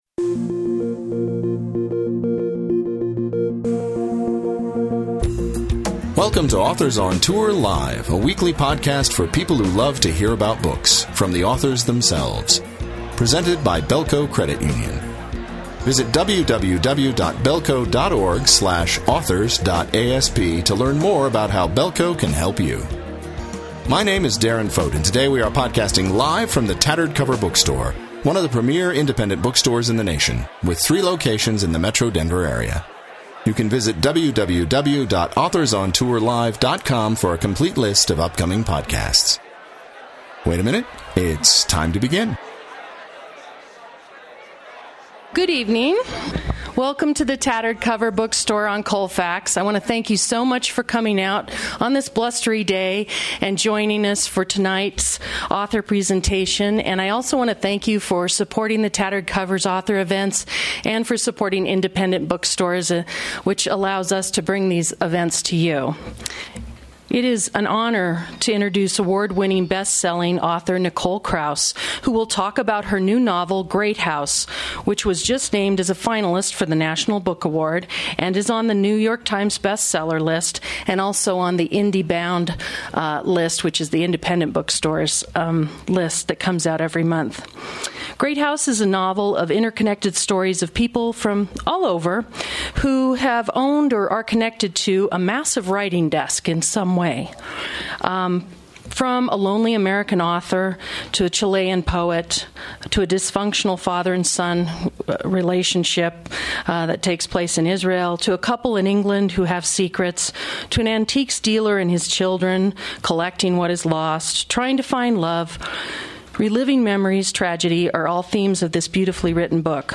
Nicole Krauss, author of the international bestseller The History of Love, reads from and discusses her critically acclaimed new novel Great House, a powerful, soaring novel about a stolen desk that contains the secrets, and becomes the obsession, of the lives it passes through.